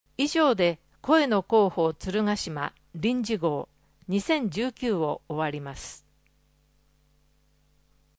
14 奥付（47秒）声の広報つるがしまは、「鶴ヶ島音訳ボランティアサークルせせらぎ」の皆さんが「広報つるがしま」の内容を音訳し、「デイジー鶴ヶ島」の皆さんがデイジー版CDを製作して、目の不自由な方々へ配布をしています。
終わりアナウンス（8秒）